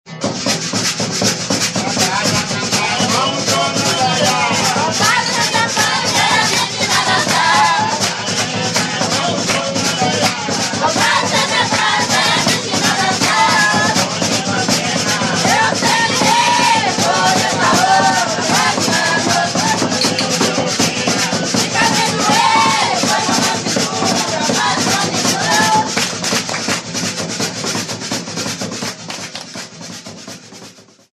Dança de pares com formação em círculo ou fileira.
Enquanto gira a roda, os dançarinos vão respondendo aos tocadores, que conduzem o canto.